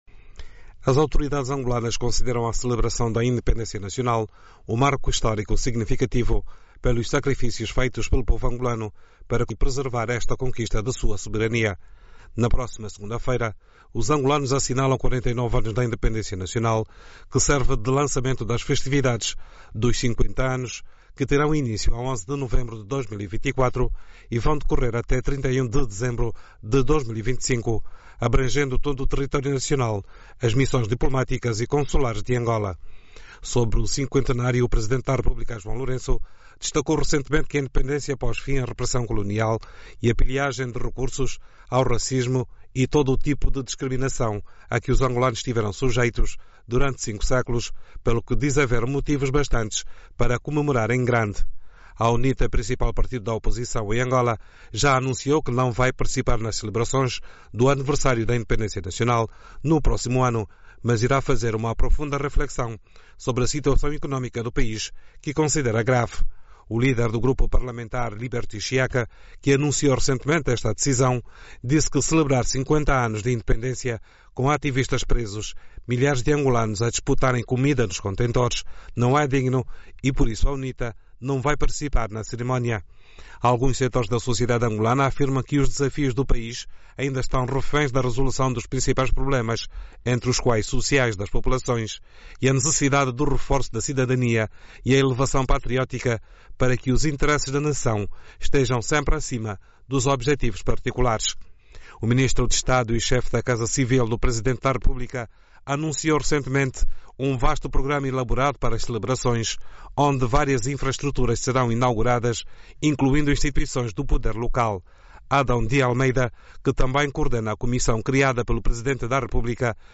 Analistas em Luanda falam do percurso da independência nacional e os desafios que Angola ainda enfrenta.